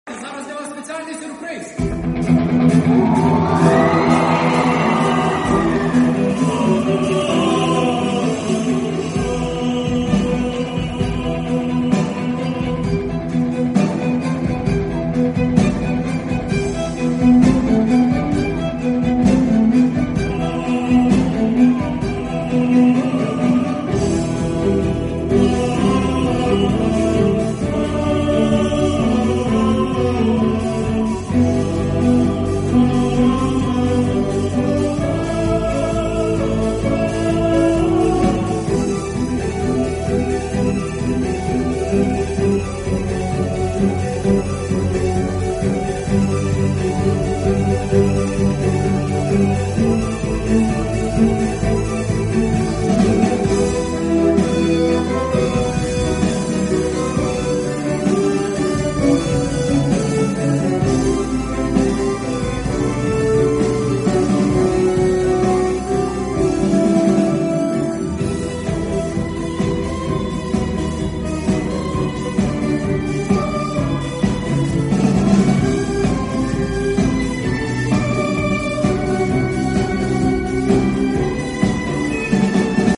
хор. Аніме концерт Київ